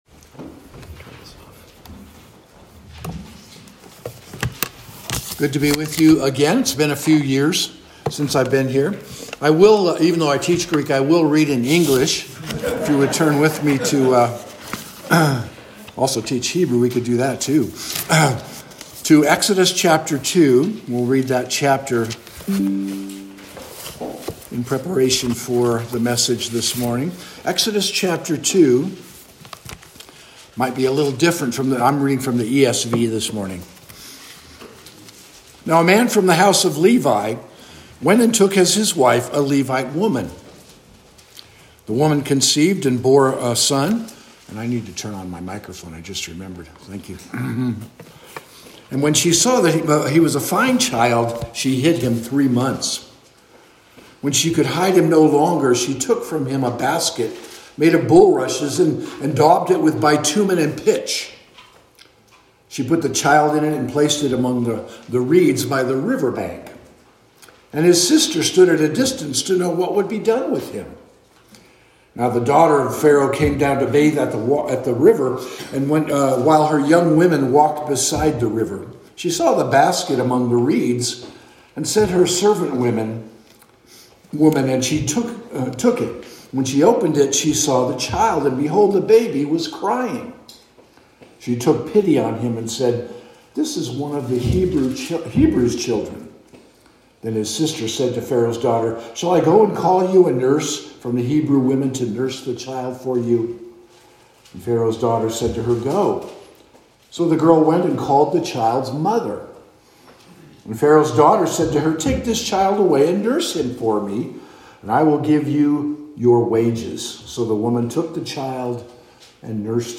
Guest Preacher Passage: Exodus 2 Service Type: Morning Service Becoming vessels fit for the Master's use.